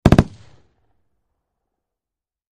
HK-21 Machine Gun Burst From Medium Point of View, X4